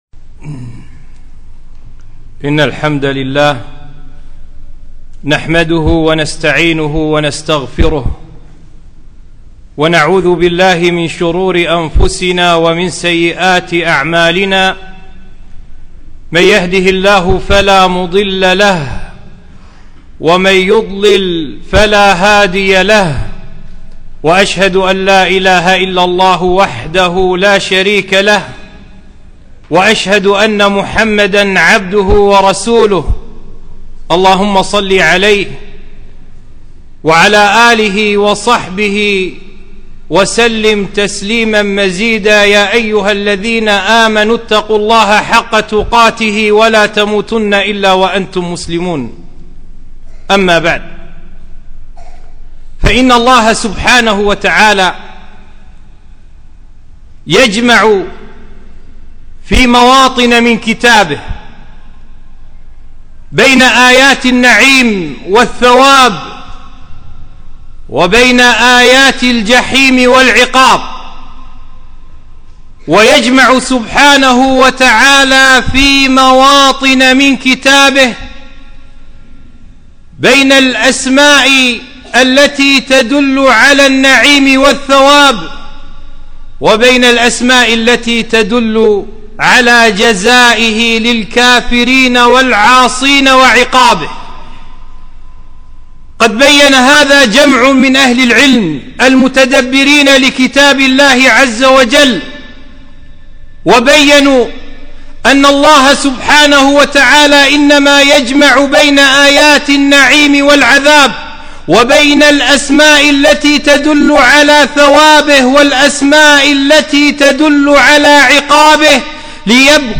خطبة - الجمع بين الرجاء والخوف